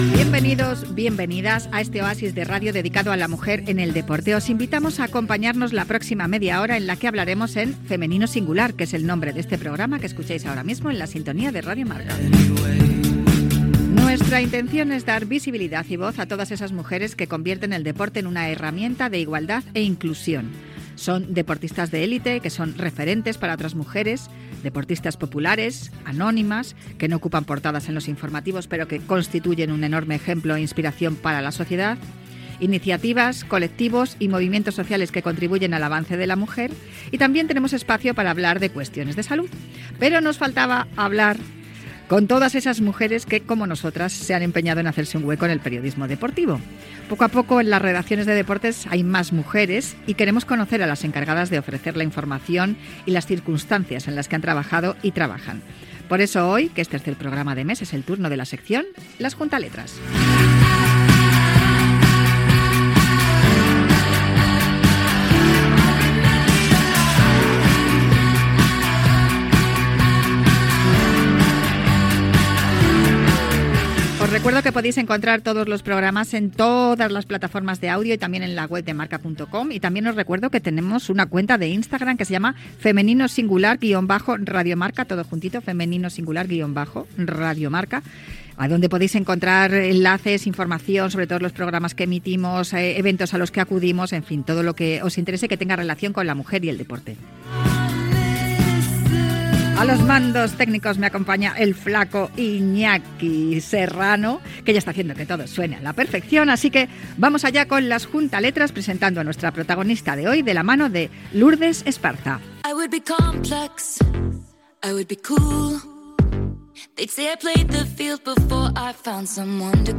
Programa d'entrevistes amb dones relacionades amb l'esport.